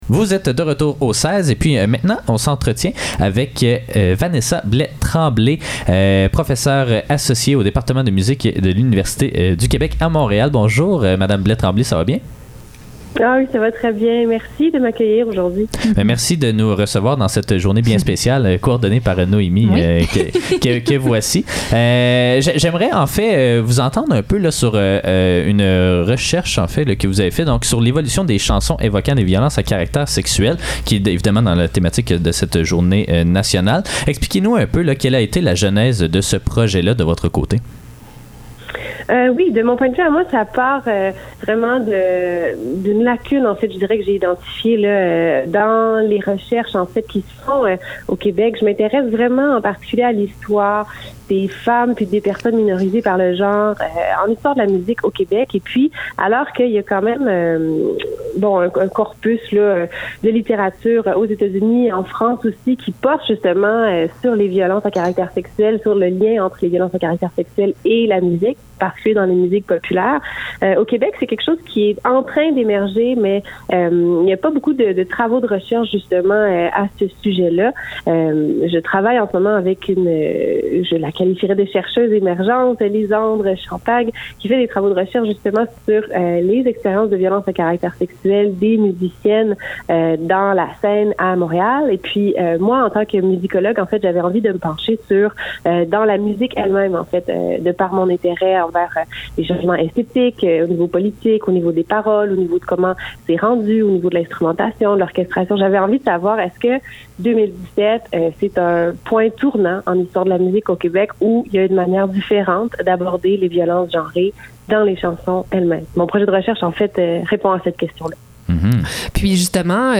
Les entrevues de CFAK Le seize - Entrevue